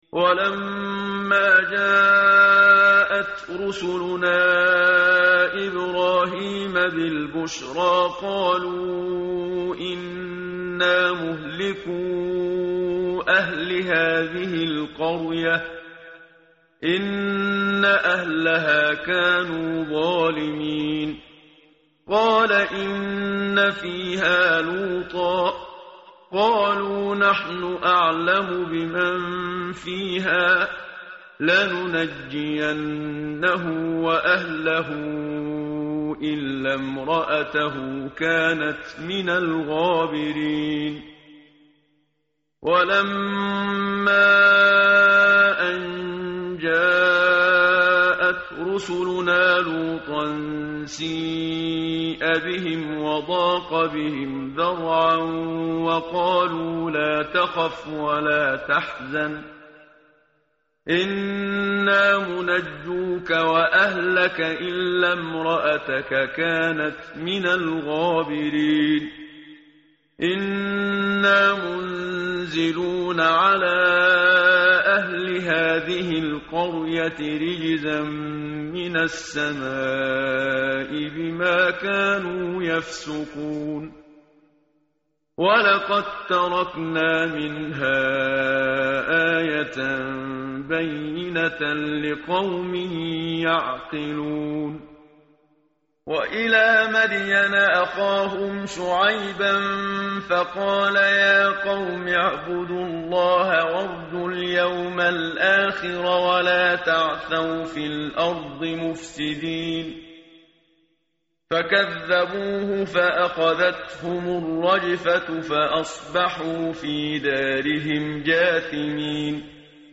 tartil_menshavi_page_400.mp3